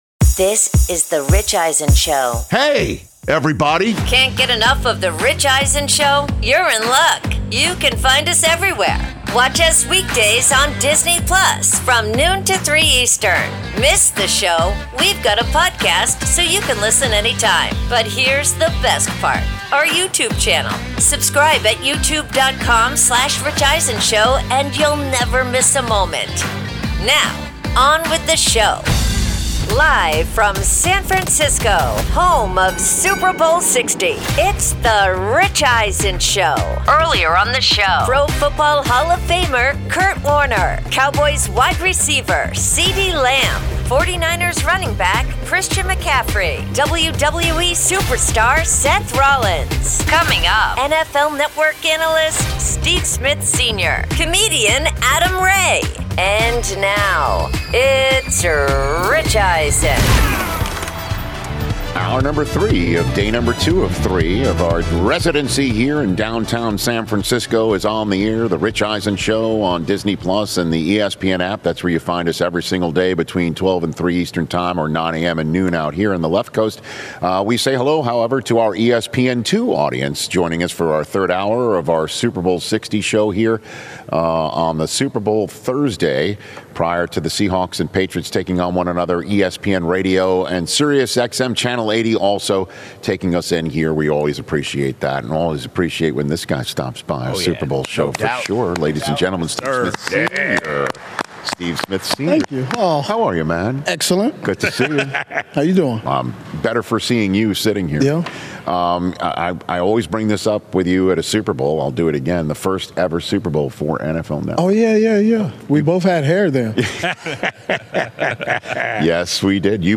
Hour 3: Live from Super Bowl LX with Steve Smith Sr. & Adam Ray, plus Higher Register Podcast with Rich Eisen